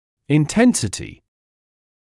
[ɪn’tensətɪ][ин’тэнсэти]интенсивность; напряжённость; яркость